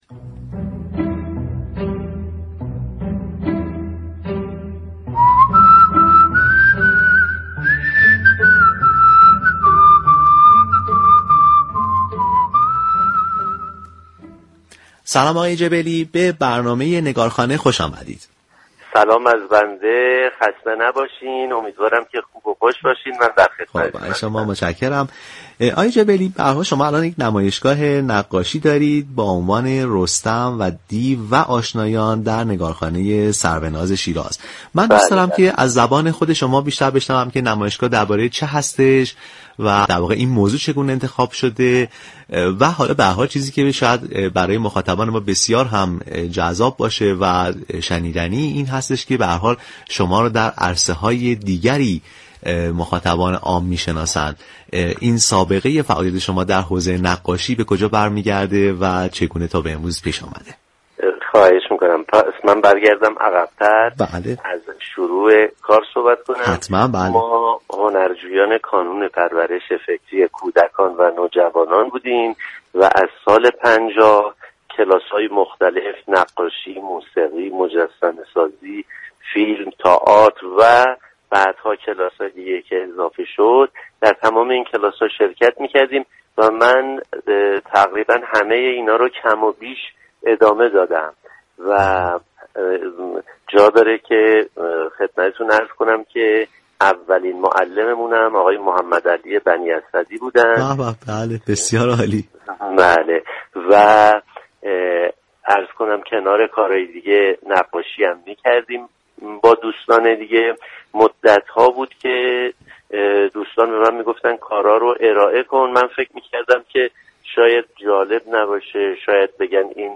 نمایشگاهی از آثار نقاشی حمید جبلی با عنوان رستم و دیو و آشنایان در نگار خانه ی سروناز شهر شیراز بر پا شد . به همین بهانه روز گذشته در برنامه ی نگار خانه با حمید جبلی گفتگویی انجام شد .